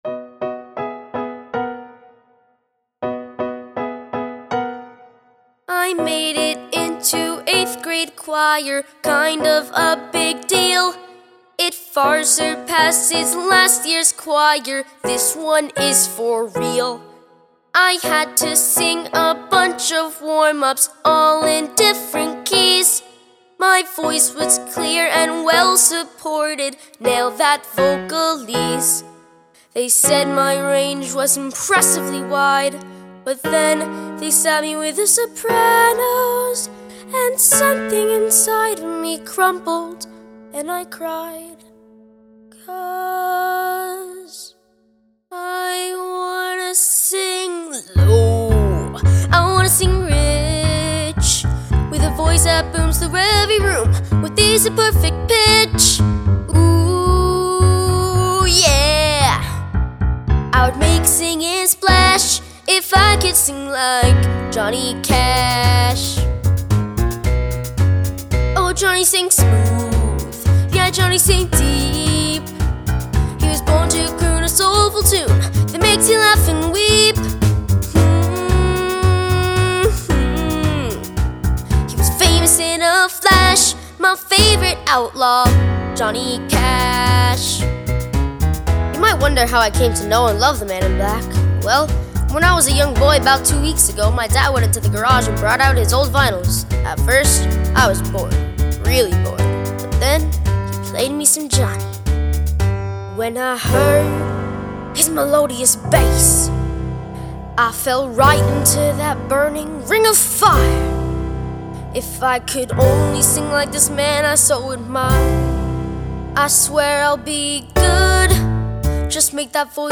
Complete Vocal Demos